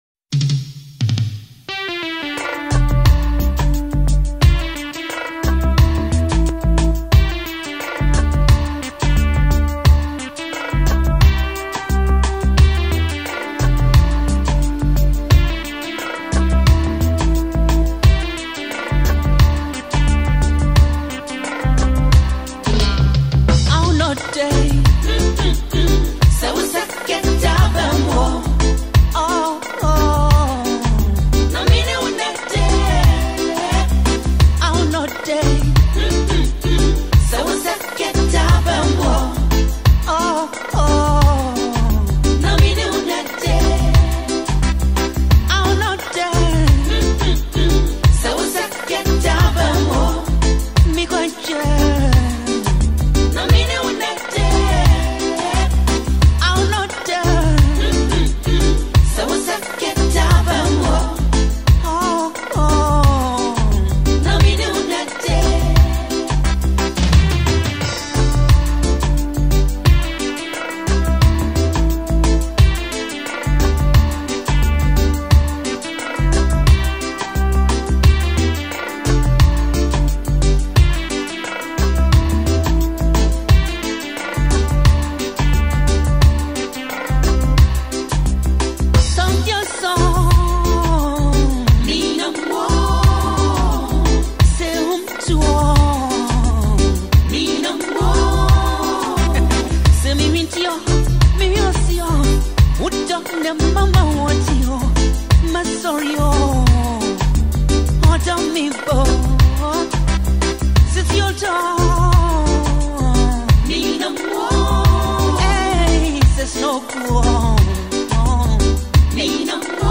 a deeply emotional and reflective highlife track
With his smooth, golden voice and poetic lyrics